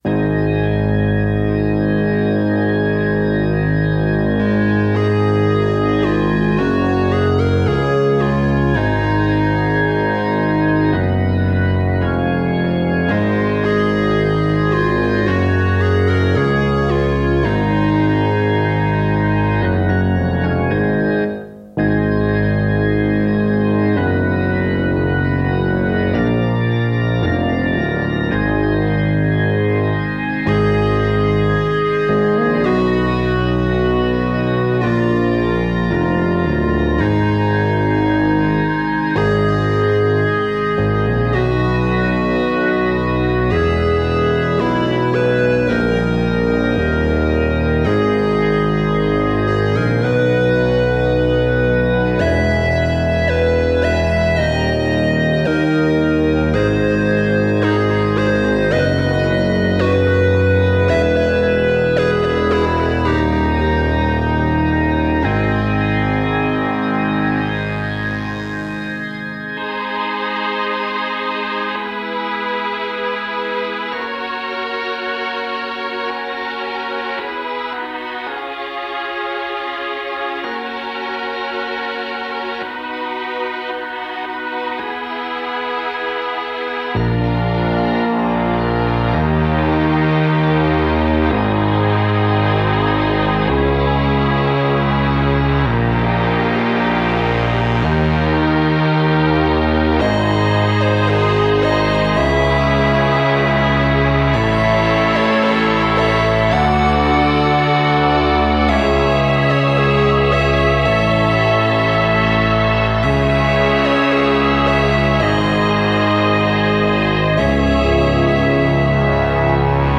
instrumental
Recorded AAD - Analogue Analogue Digital